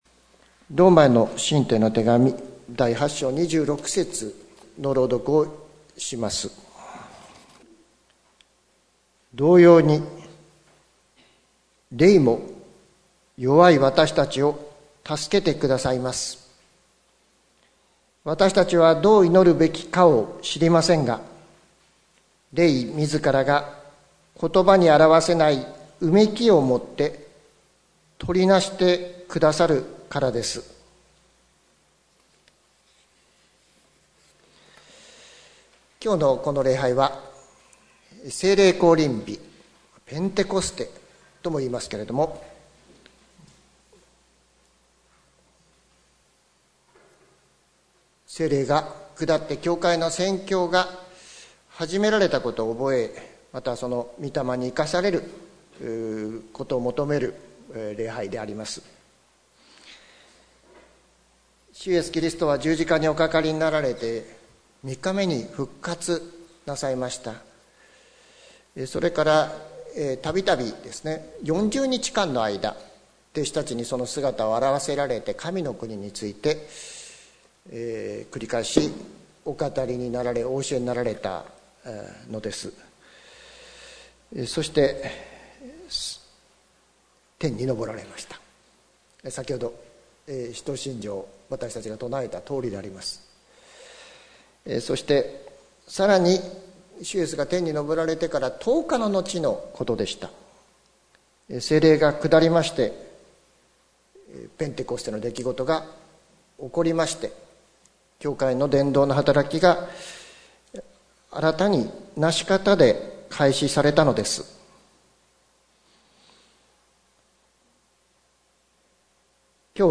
説教アーカイブ。
今日は、聖霊降臨日、ペンテコステの礼拝です。